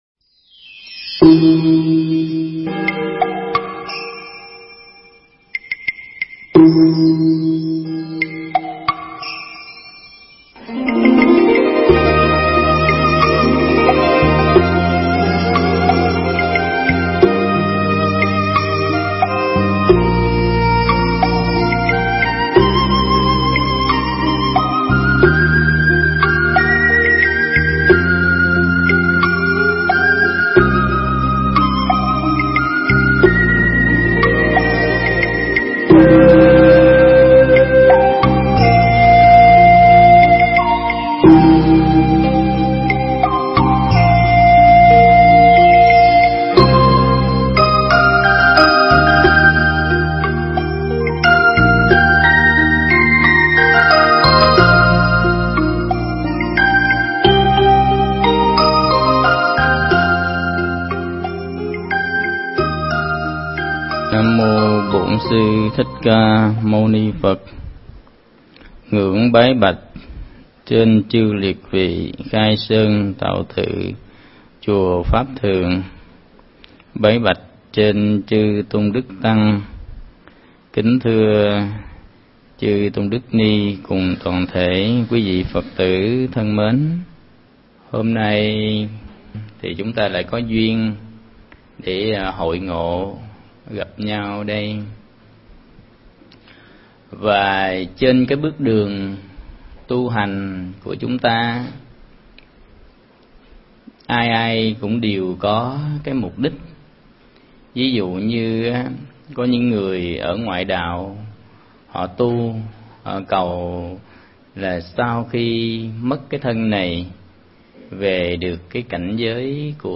Mp3 Thuyết Giảng Vững Bước Trước Phong Ba
giảng tại Chùa Pháp Thường, Nhơn Trạch - Đồng Nai